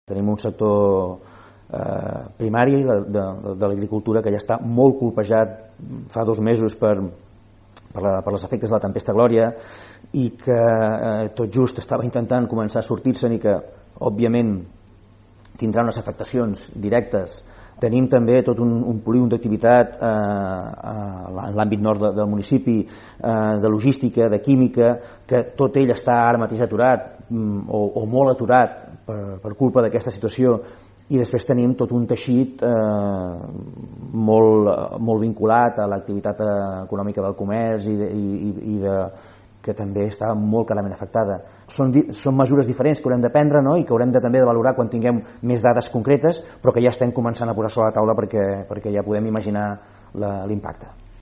Dues setmanes després del decret d’estat d’alarma i del confinament obligat per evitar l’expansió del coronavirus, l’alcalde de Palafolls, Francesc Alemany, va concedir una entrevista a RP en la que va repassar l’actualitat d’aquests últims 15 dies i de l’estat actual de Palafolls en la lluita contra la COVID19.